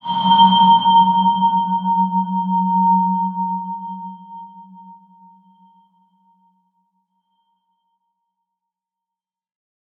X_BasicBells-F#1-pp.wav